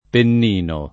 DOP: Dizionario di Ortografia e Pronunzia della lingua italiana
pennino